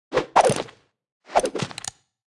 Media:Sfx_Anim_Classic_Colt.wavMedia:Sfx_Anim_Super_Colt.wavMedia:Sfx_Anim_Ultra_Colt.wavMedia:Sfx_Anim_Ultimate_Colt.wav 动作音效 anim 在广场点击初级、经典、高手、顶尖和终极形态或者查看其技能时触发动作的音效
Sfx_Anim_Classic_Colt.wav